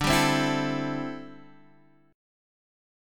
D9sus4 chord